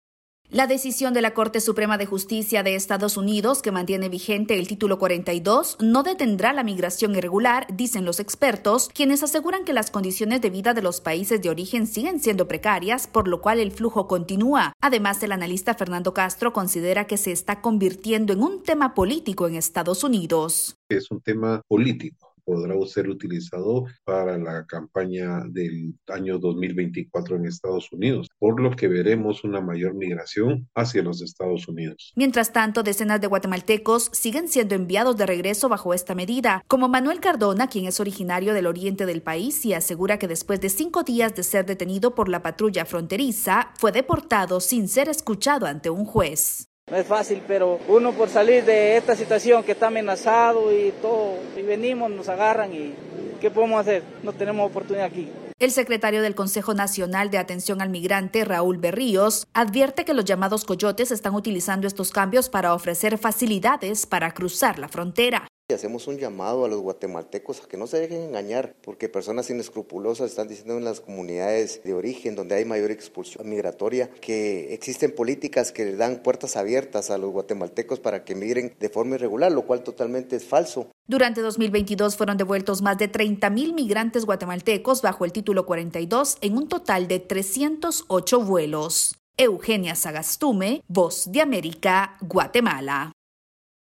Diversas reacciones género en Guatemala la continuidad del Título 42. El informe